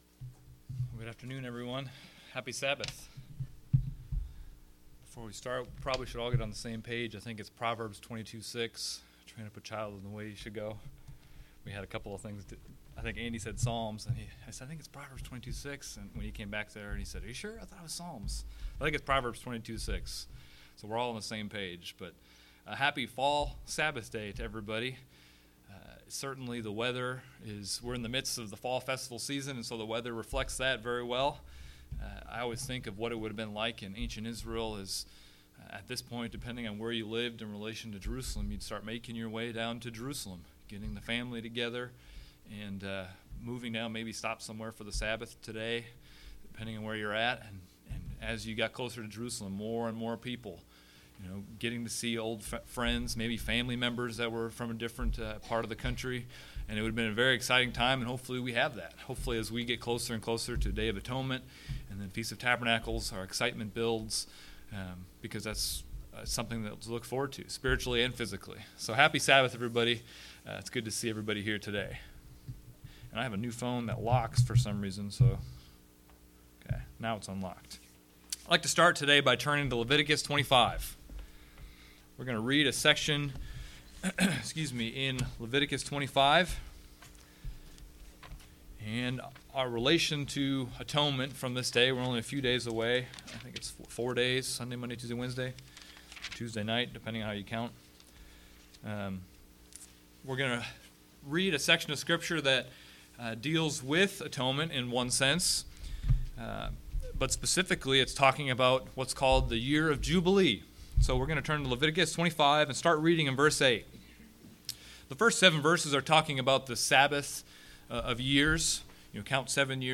God Instructed Israel to celebrate a "Jubilee" every 50 years. This Jubilee year was to be announced with a Trumpet on the Day of Atonement. This sermon looks at 3 themes that connect the Jubilee and the Day of Atonement, and looks at how these themes should affect our lives today.
Given in Springfield, MO